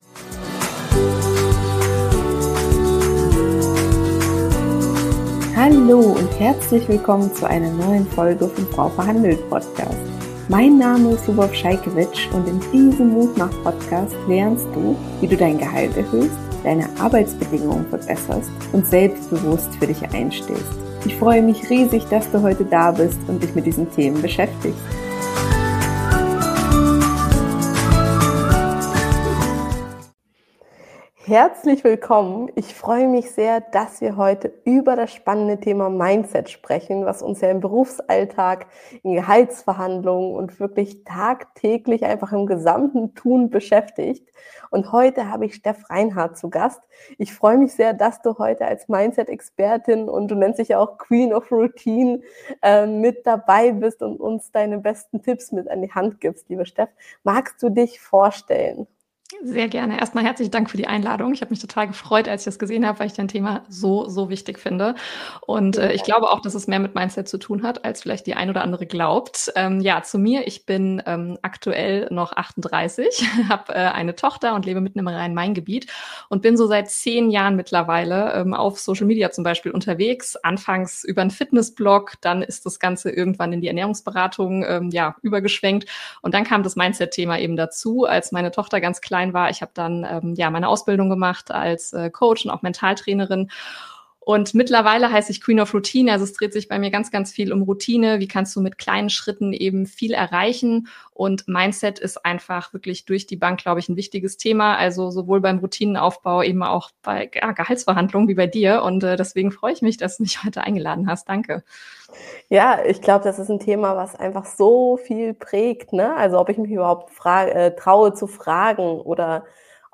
#079: So stärkst du dein Mindset fürs Gehaltsgespräch - Interview